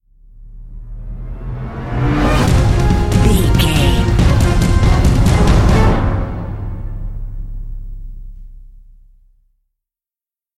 Epic / Action
Fast paced
In-crescendo
Aeolian/Minor
A♭
Fast
strings
drums
orchestral
orchestral hybrid
dubstep
aggressive
energetic
intense
powerful
bass
synth effects
wobbles
driving drum beat